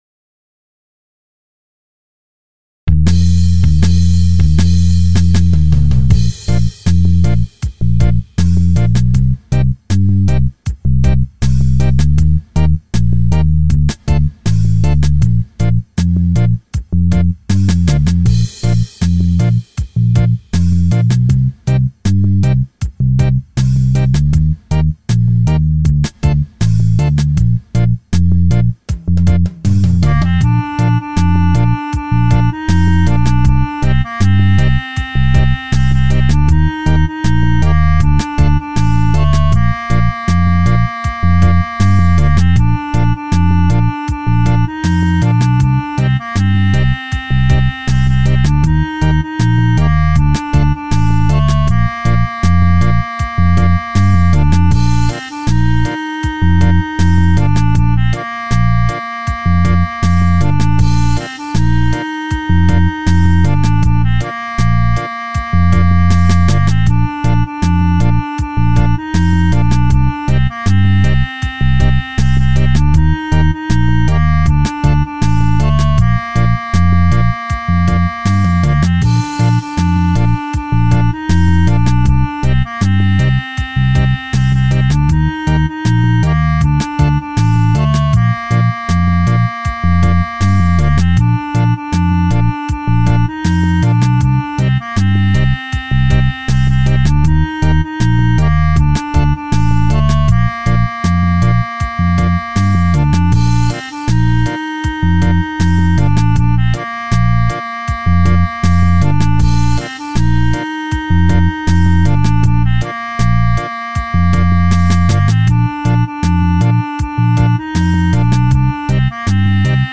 Archivo de audio MP3  del canto tradicional de La Aurora en versión reggae tocada con gaita aragonesa y guitarra.
aurora reggae.mp3